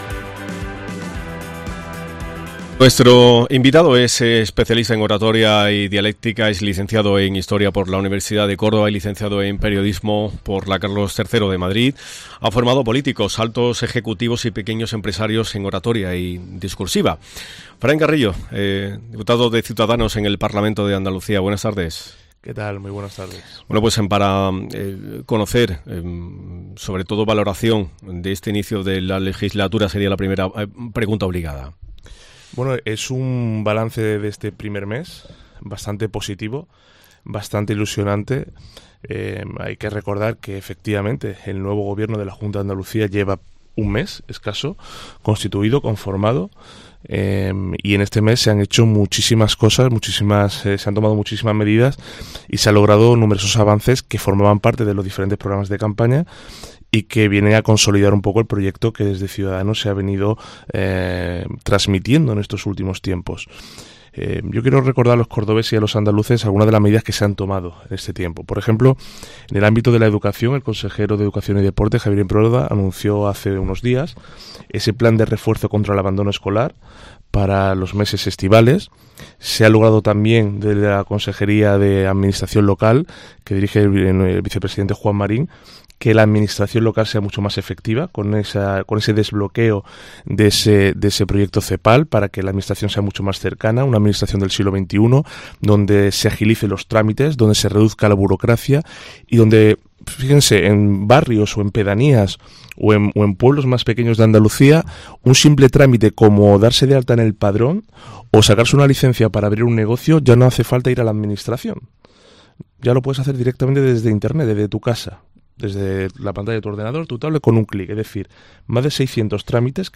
El diputado autonómico ha pasado esta mañana por los micrófonos de COPE
Fran Carrillo, parlamentario andaluz por Ciudadanos